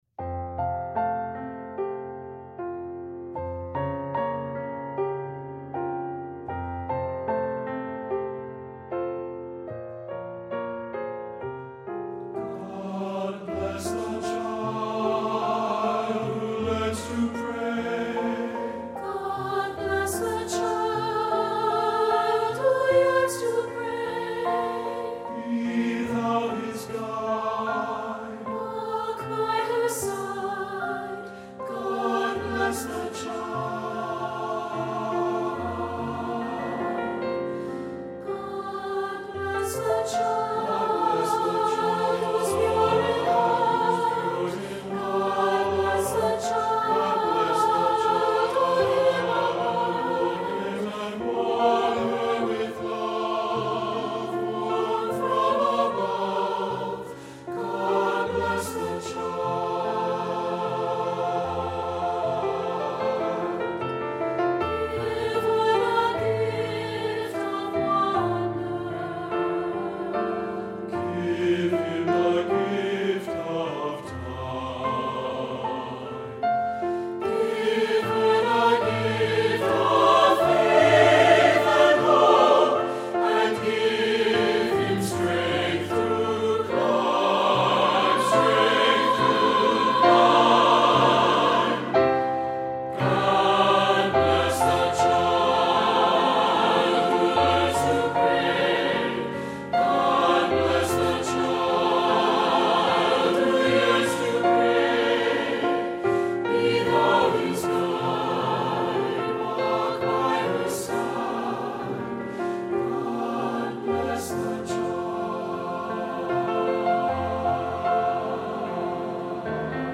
Voicing: SATB